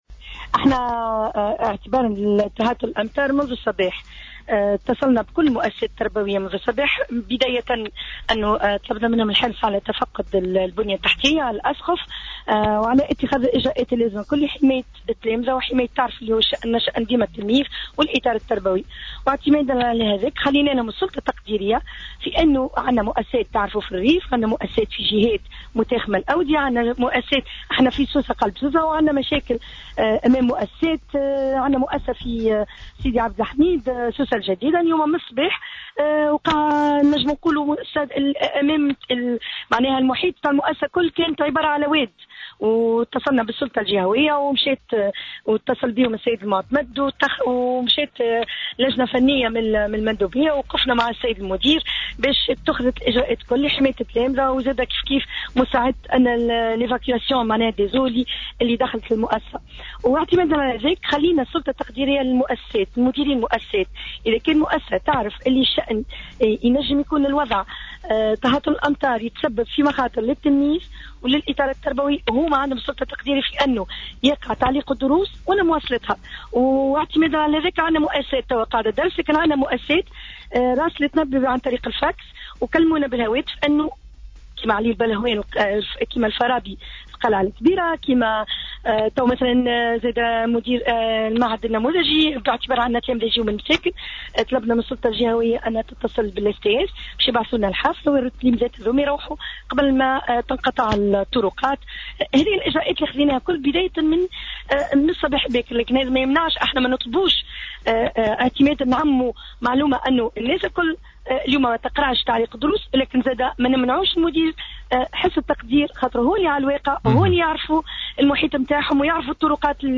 أكدت المندوبة الجهوية للتربية بسوسة سميرة خذر في مداخلة لها على الجوهرة "اف ام" أنه واعتبارا لتهاطل الأمطار منذ صباح اليوم اتصلت بجميع مديري المؤسسات التربوية بسوسة اليوم ودعتهم للحرص على تفقد البنية التحتية والأسقف واتخاذ الاجراءات اللازمة لحماية التلاميذ .